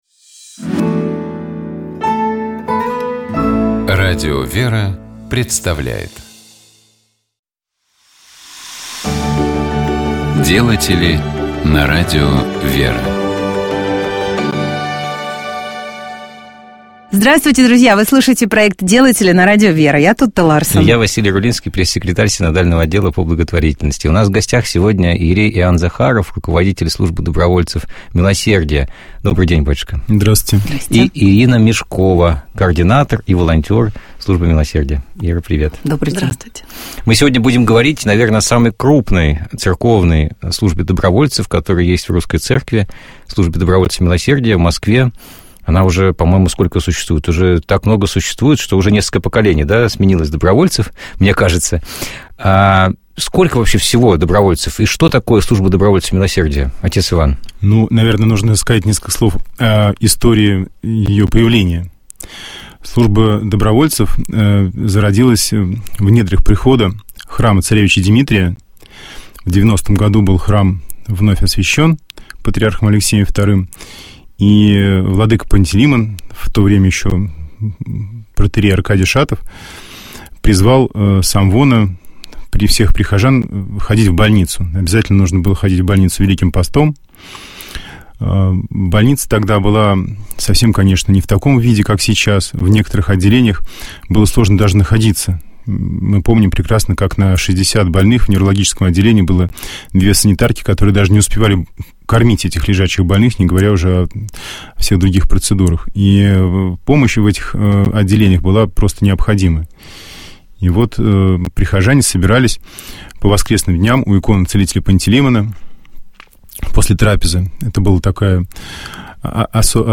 Вы слушаете проект «Делатели» на Радио ВЕРА, я Тутта Ларсен...